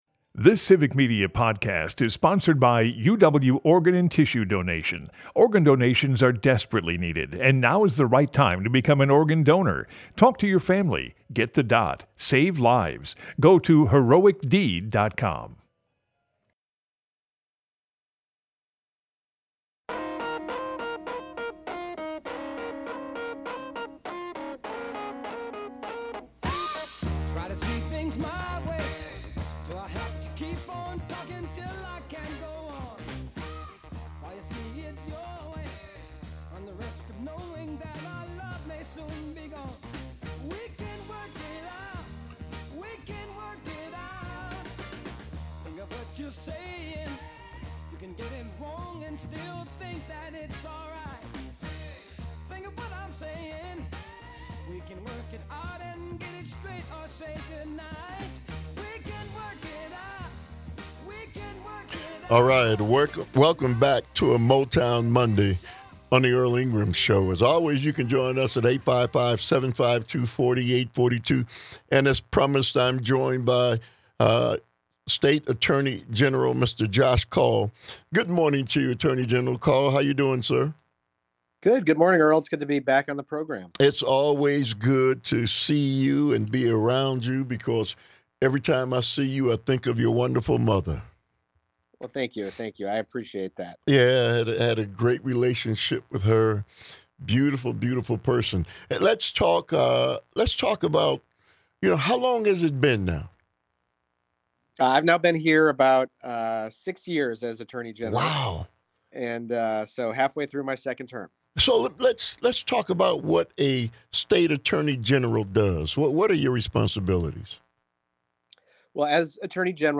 Broadcasts live 8 - 10am weekdays across Wisconsin.